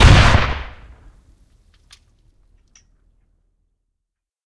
rpgShoot.ogg